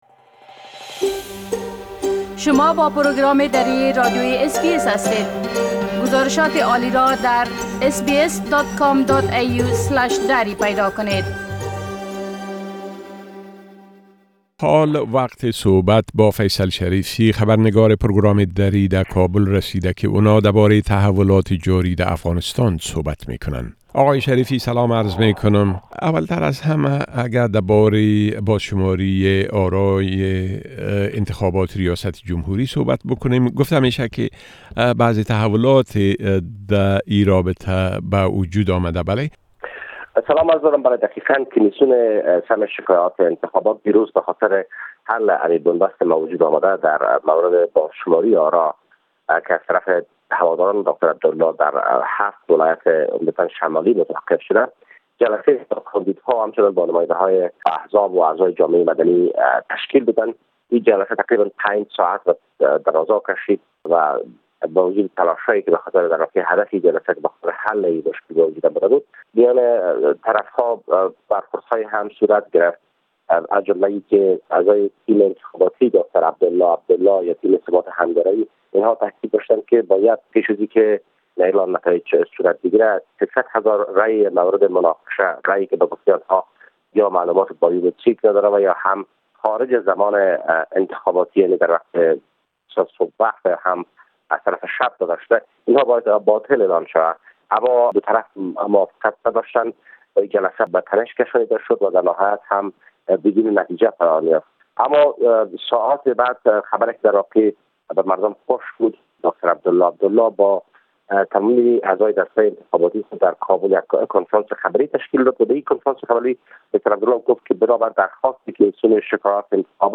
گرازش كامل خبرنگار ما در كابل بشمول گمانه زنى ها در مورد متوقف شدن مذاكرات صلح بين امريكا و گروۀ طالبان و رويداد هاى مهم ديگر در افغانستان را در اينجا شنيده ميتوانيد.